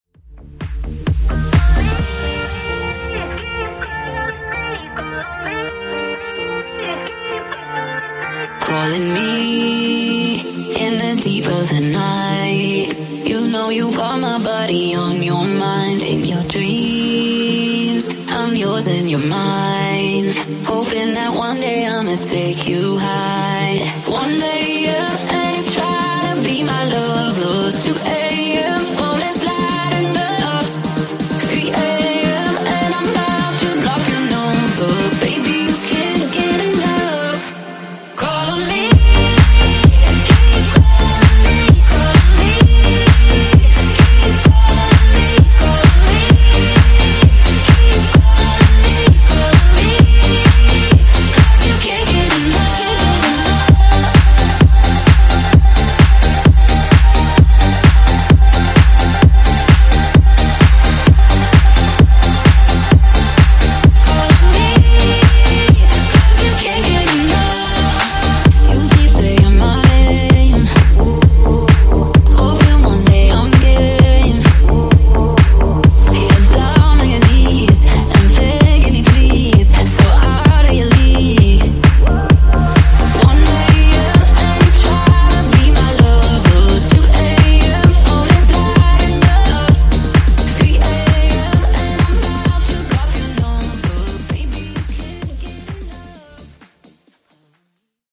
Genre: RE-DRUM
Clean BPM: 130 Time